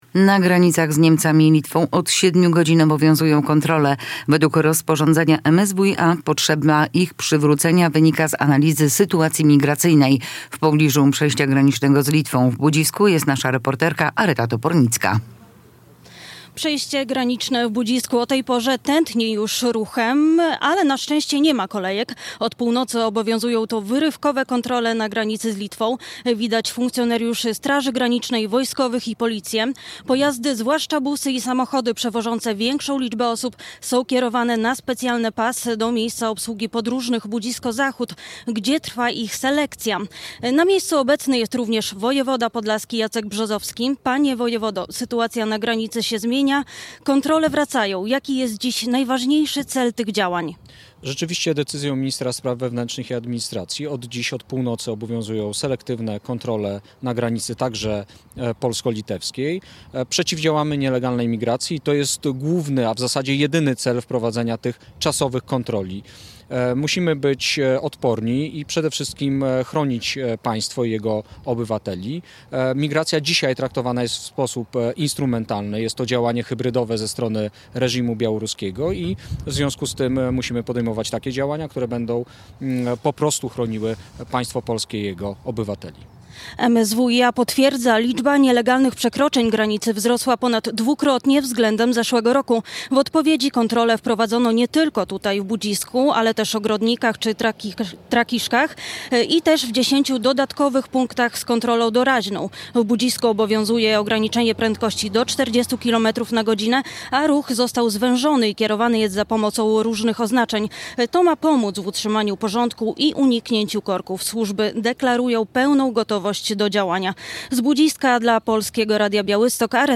Kontrole na granicy w Budzisku wróciły - relacja